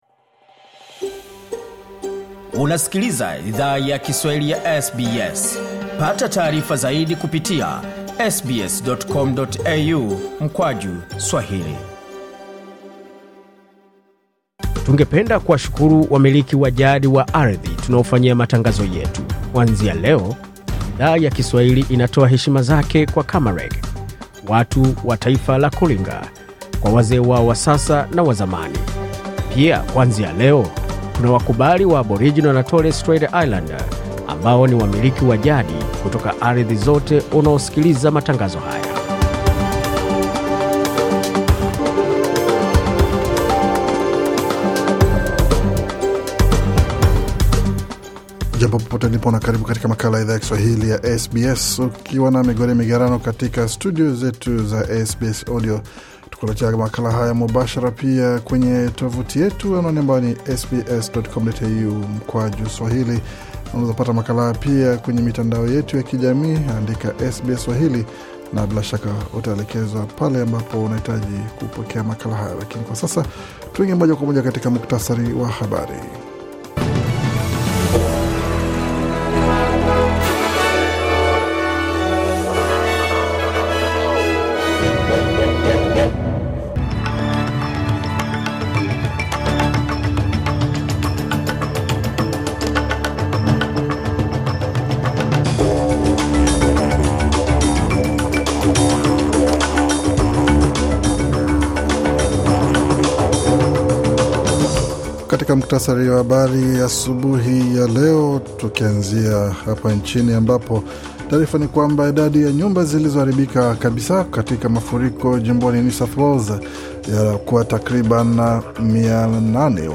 Taarifa ya Habari 27 Mei 2025